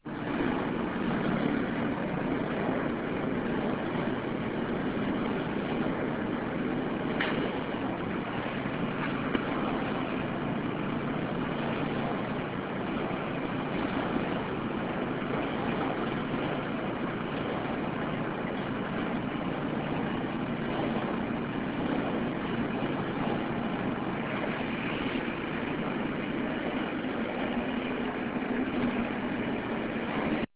laundry room – Hofstra Drama 20 – Sound for the Theatre
Location: Vander Poel laundry room
Sounds heard: Washers running, dryers running, washer door closing, footsteps.